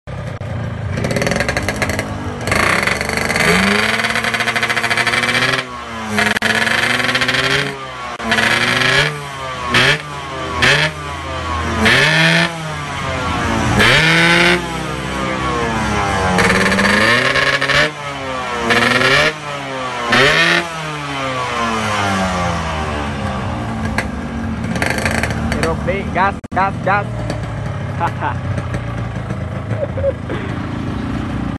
spek boring taka porting knalpot al tech anti reyen club, stel basah saja 😁😬